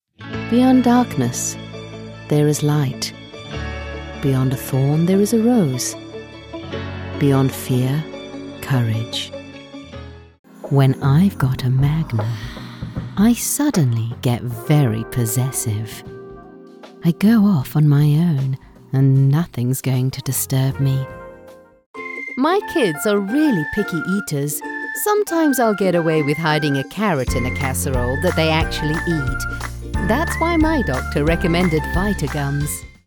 Female
English (South African), English (Neutral - Mid Trans Atlantic)
My voice is: Sensual, Calming, Engaging, Bright, Expressive, Intelligent, Authoritative